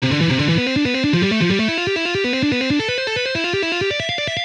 Guitar Lessons Page 1
C Major String Skipping #1
C Major String Skip.wav